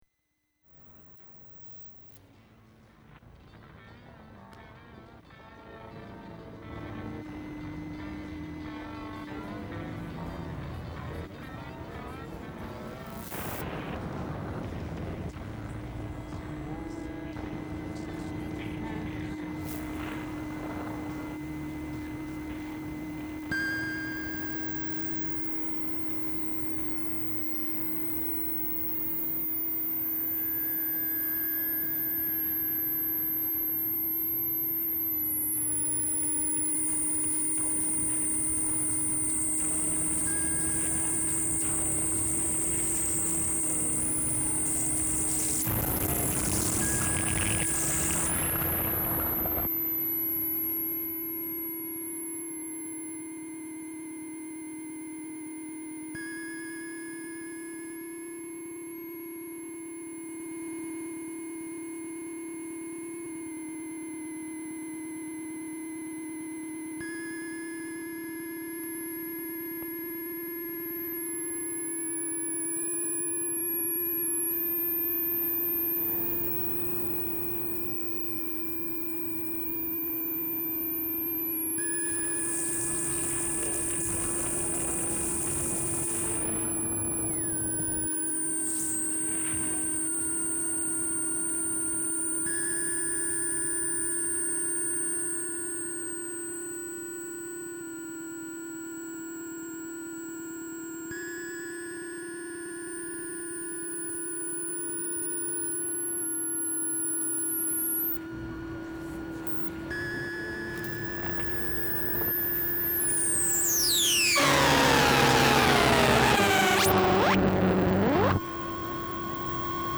Nouvelles Déesses — Epsilon Boötis, un des messages captés par Alouette.
Réactions à l’activité lumino-électrique environnante de façon criarde, vivante et sensuelle. Envolées de sons pleins de parasites et de respirations.